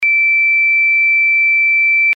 ZUMBADOR - SONIDO CONTINUO Y/O INTERMITENTE
Zumbador Electrónico Empotrable ø 28 mm
90 dB (80 dB con protector)
Continuo